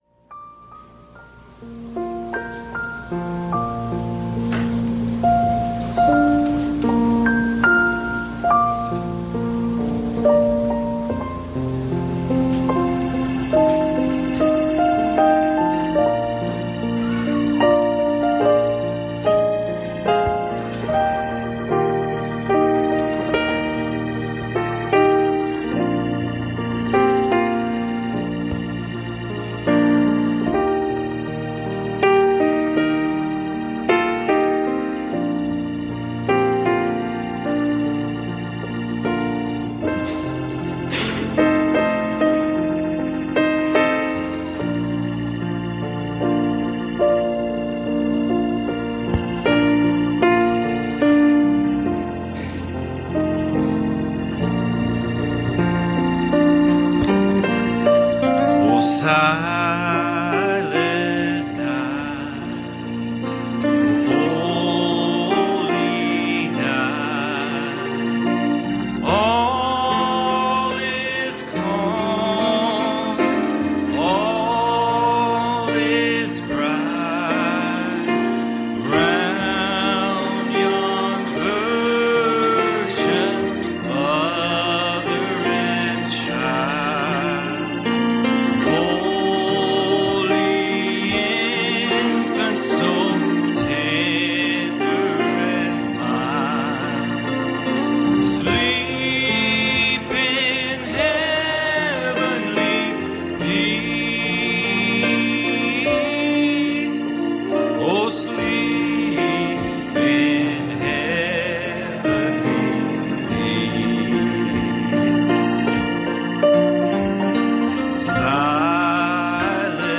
Series: Sunday Morning Services Passage: John 1:14 Service Type: Sunday Morning